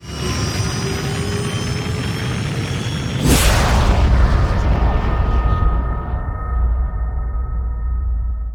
TheExperienceLight - A lightened version of the official VIP The Experience soundpack.
gravjump.wav